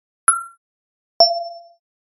synthetic pings, light-bulb, indicator